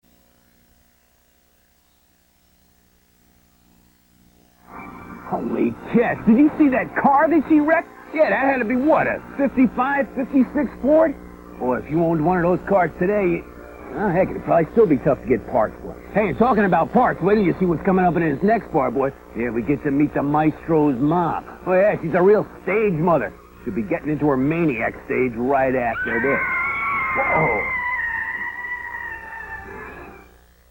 Leveller - Moderate